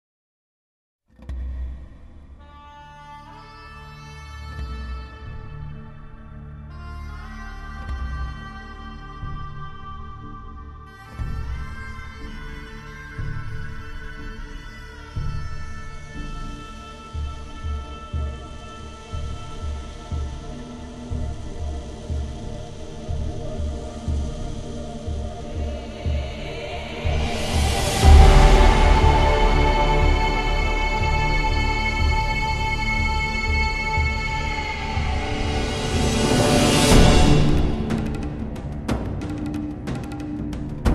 Arabic vocals and Shawm